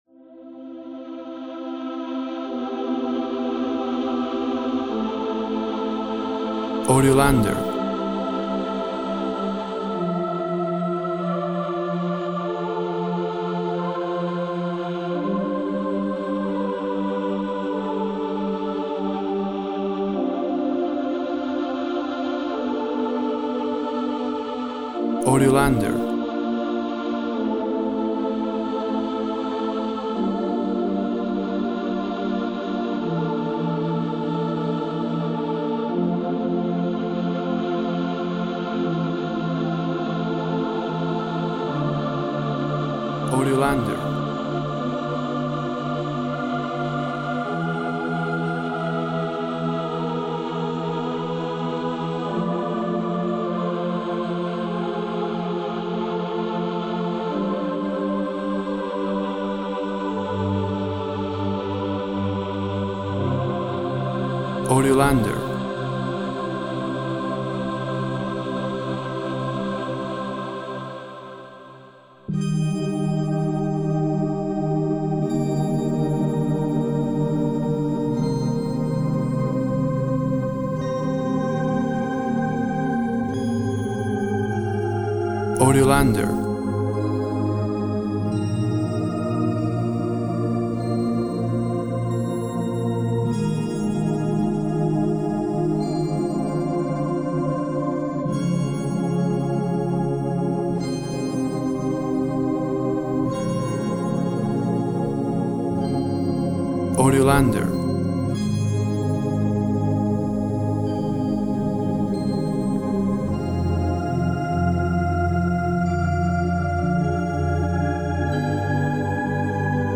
Choir and synths create a magical atmosphere.
Tempo (BPM) 44